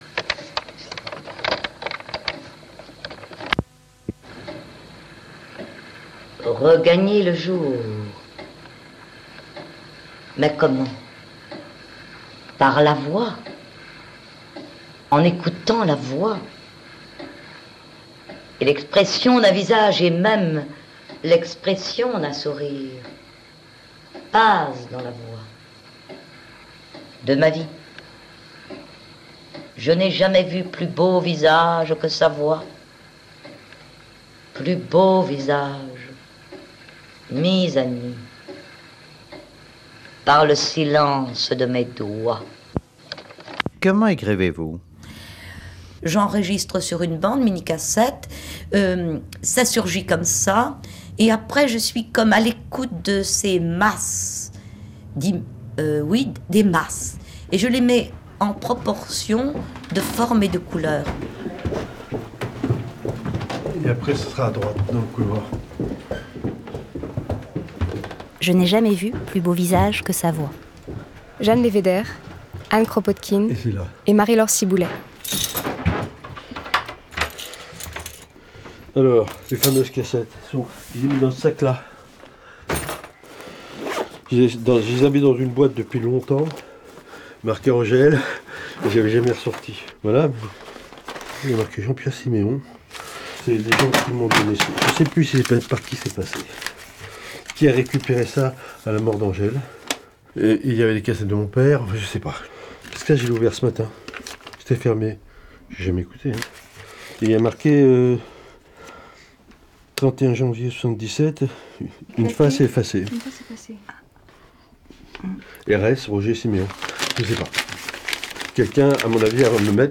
Elles font entendre la voix flamboyante et l’univers haut en couleur de cette artiste étonnamment oubliée.